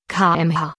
Ansage Geschwindigkeit